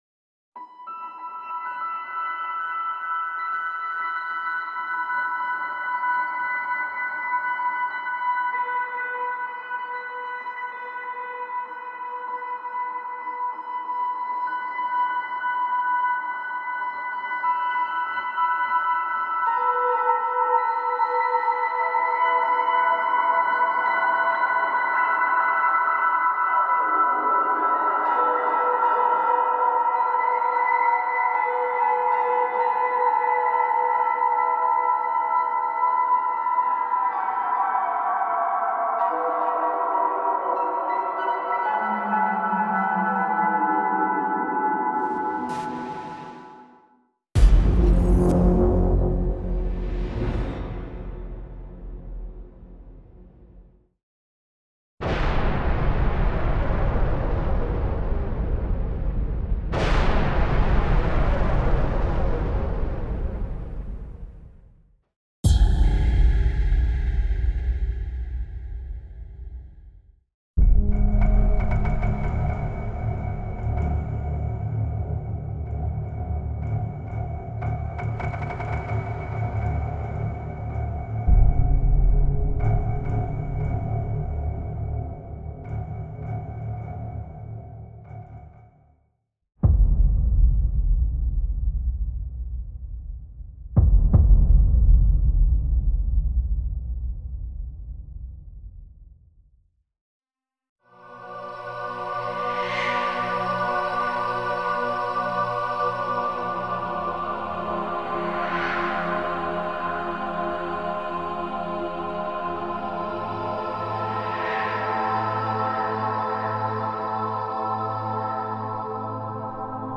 Ambient Cinematic / FX Psy-Trance Trance
CineTrance Movements gives you 64 Omnisphere presets of granular, epic and emotional sounds.